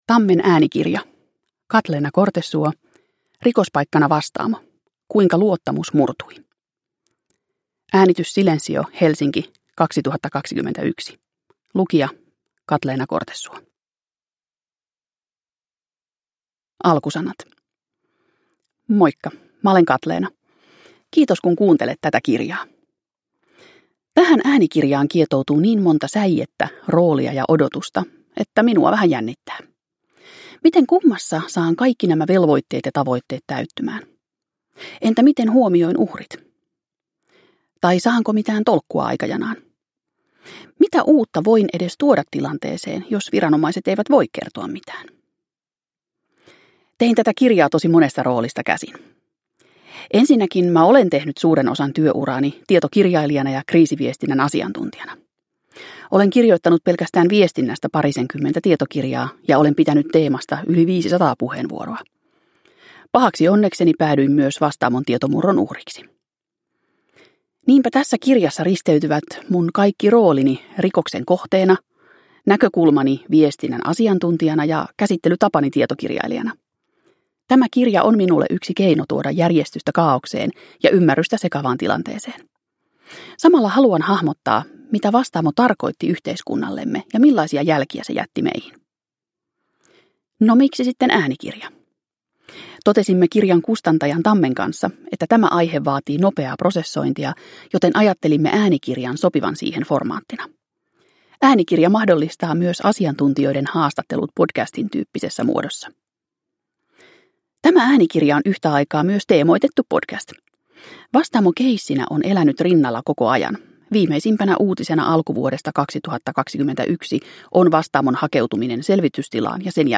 Rikospaikkana Vastaamo on uudenlainen yhdistelmä perinteistä äänikirjaa ja haastattelumuotoista podcastia.